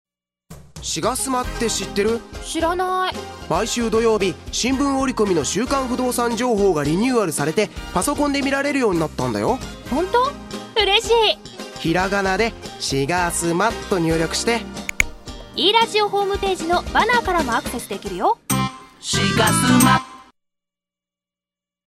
現在ラジオCMを”e-radio”の愛称でおなじみFM滋賀で放送中です♪